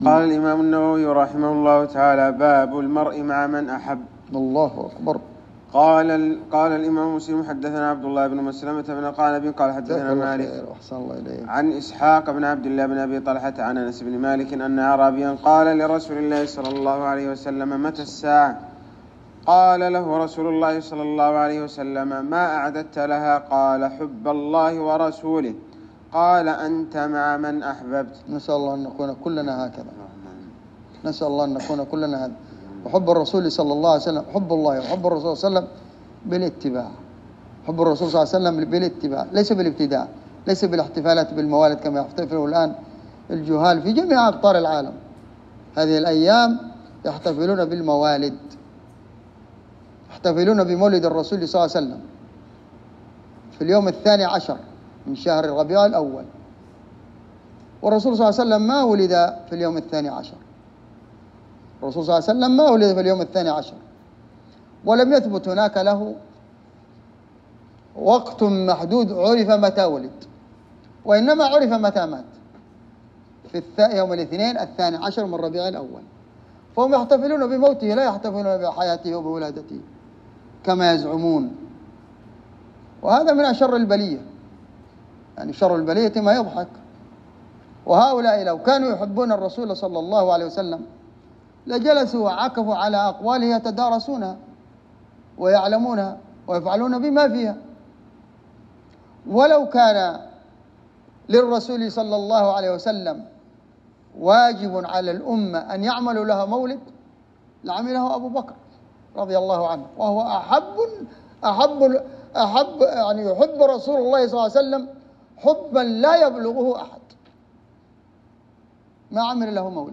- الدرس الخامس من شرح كتاب البر والصلة والآداب من صحيح مسلم/ باب المرء مع من أحب